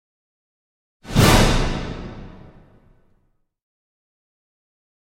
Звуковые эффекты магии, трансформации и перевоплощения идеально подойдут для монтажа видео, создания игр, подкастов и других творческих проектов.